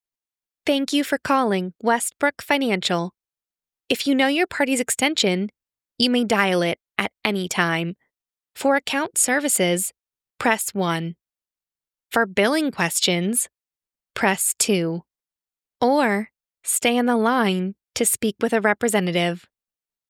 IVR
English - USA and Canada
Young Adult